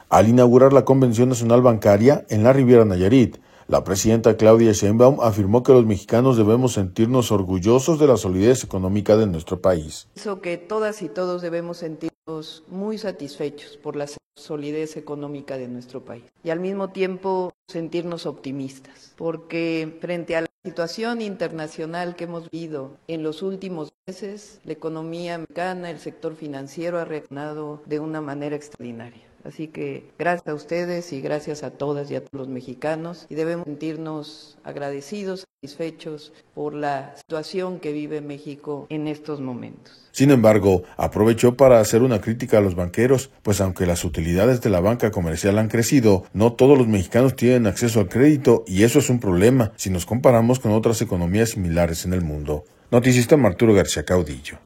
Al inaugurar la Convención Nacional Bancaria, en la Riviera Nayarit, la presidenta Claudia Sheinbaum, afirmó que los mexicanos debemos sentirnos orgullosos de la solidez económica de nuestro país.